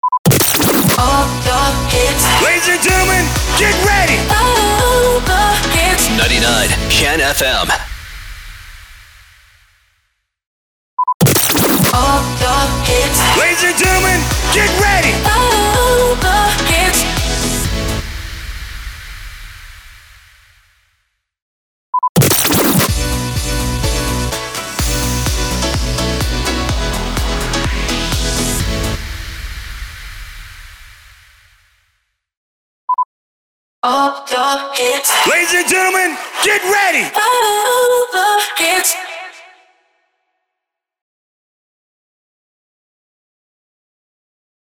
637 – SWEEPER – ALL THE HITS